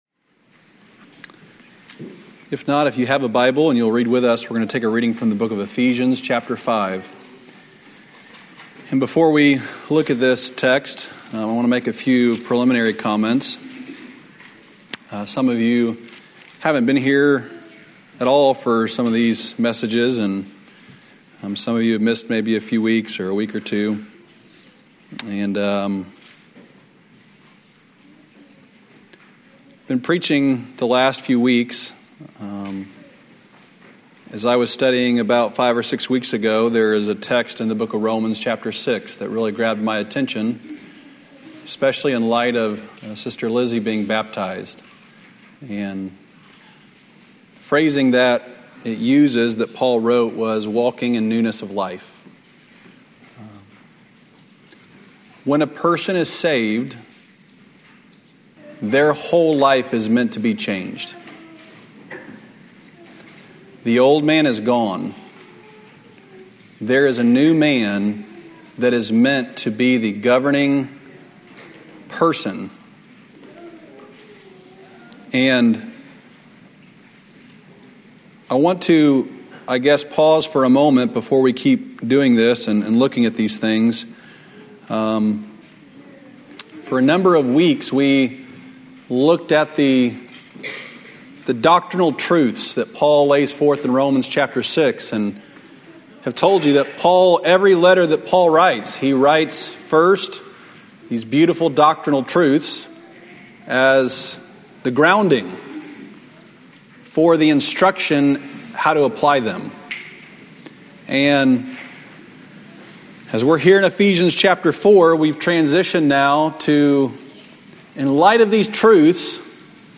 Lesson 1 from the 2009 Old Union Ministers School.